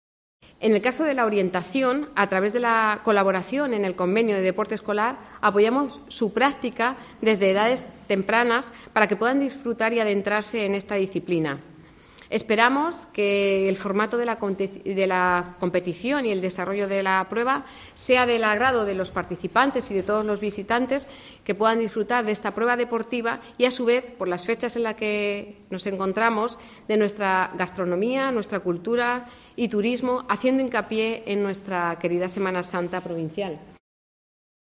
Milagros Calahorra, vicepresidenta de la Diputación y responsable del área de deportes